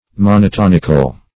Search Result for " monotonical" : The Collaborative International Dictionary of English v.0.48: Monotonic \Mon`o*ton"ic\, Monotonical \Mon`o*ton"ic*al\, a. 1.